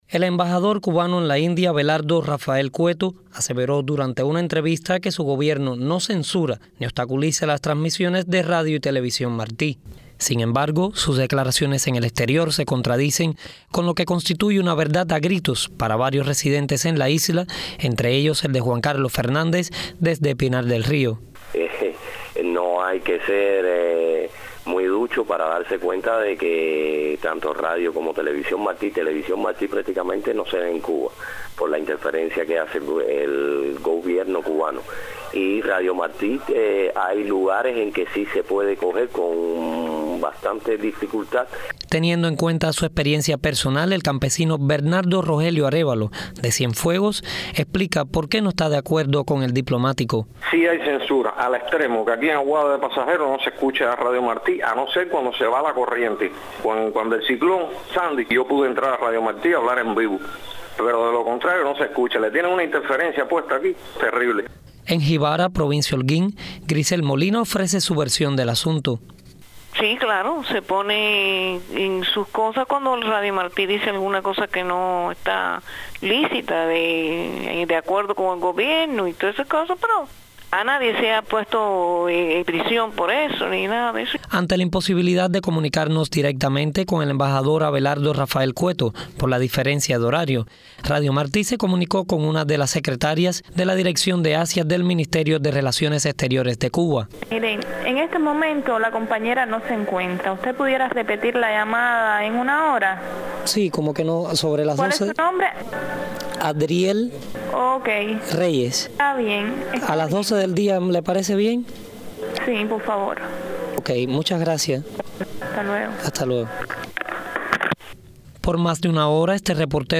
realizó varias entrevistas y tiene en informe.